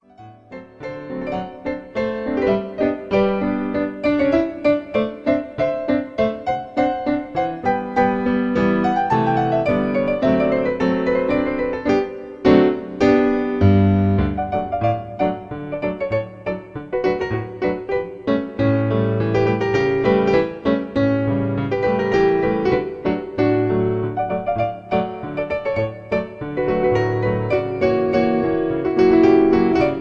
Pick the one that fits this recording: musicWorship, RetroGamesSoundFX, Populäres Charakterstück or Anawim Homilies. Populäres Charakterstück